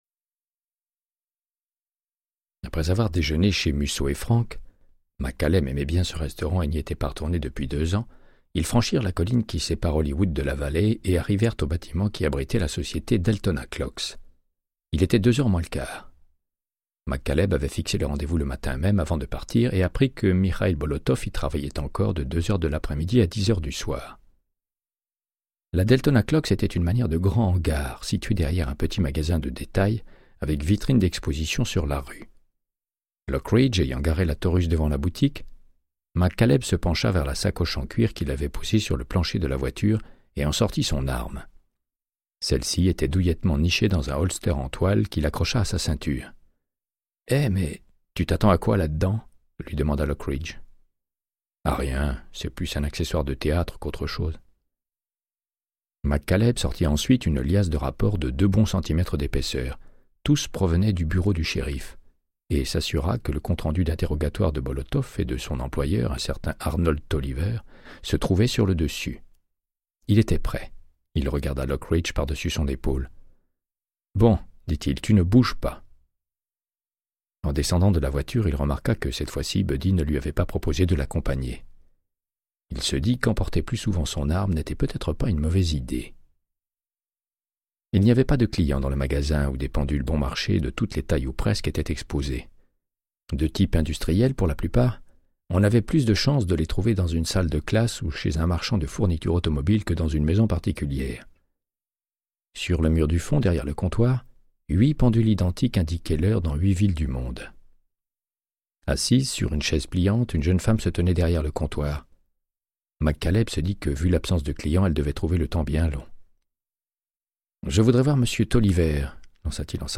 Audiobook = Créance de sang, de Michael Connellly - 59